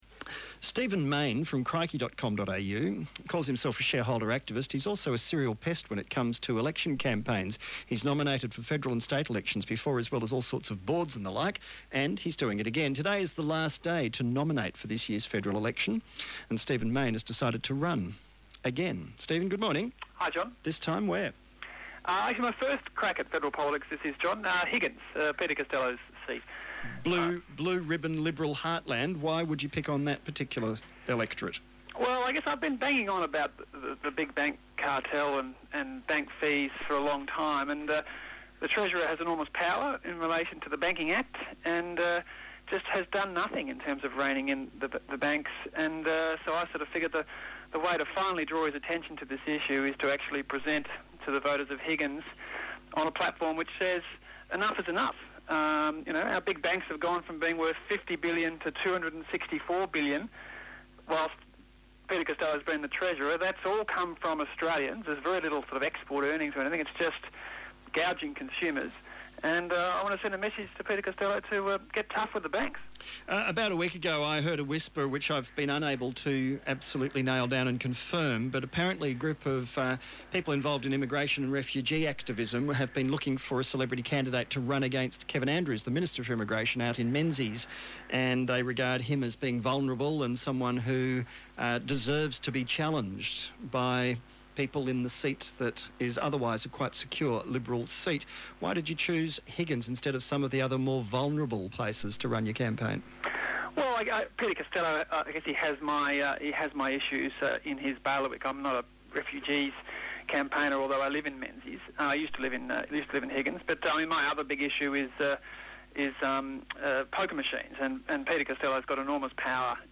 Radio Interviews
702 ABC Melbourne, Jon Faine Interview, October 31 -